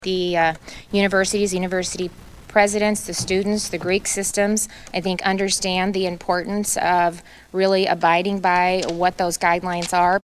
IA Gov. Kim Reynolds speaks during a Press Conference Sept. 16, 2020.